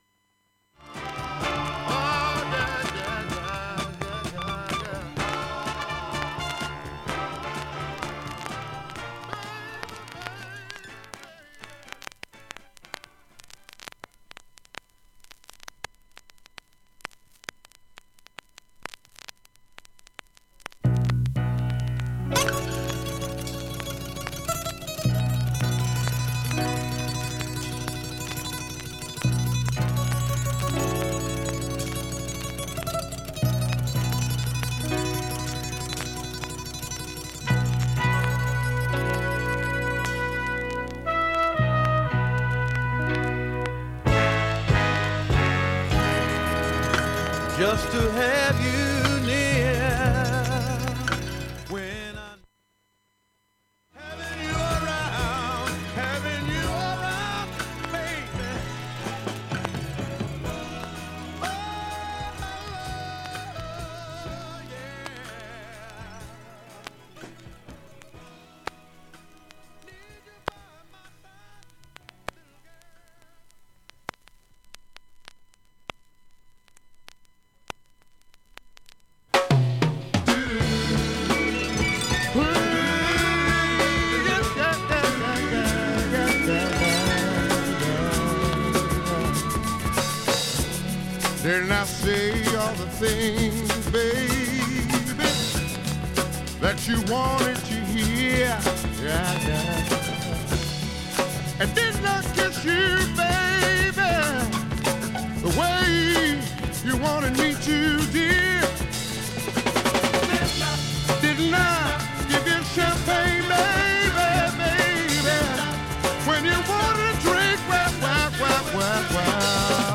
A面２，３，４の曲間にキズがあり、
1本のキズでわずかなプツ続きます。
A-3始めもありますが、すぐに解消する感じです。
アルティメット・ブレイクなファンク・チューン